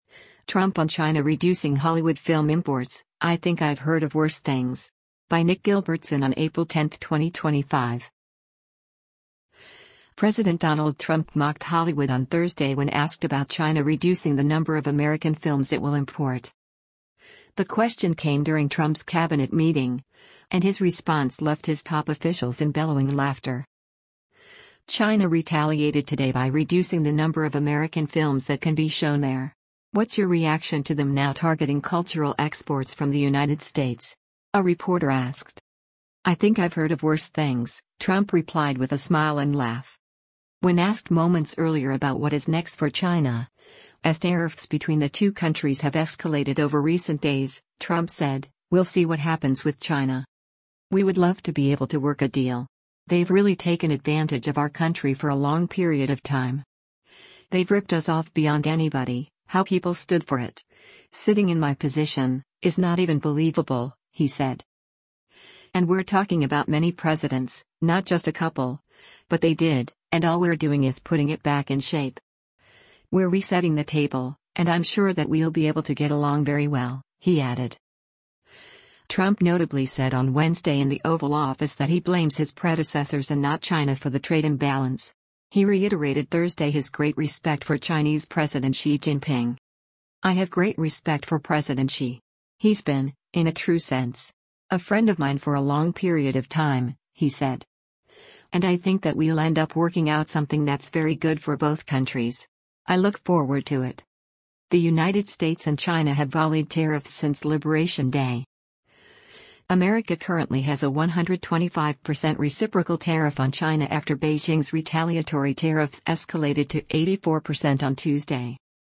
The question came during Trump’s cabinet meeting, and his response left his top officials in bellowing laughter.
“I think I’ve heard of worse things,” Trump replied with a smile and laugh.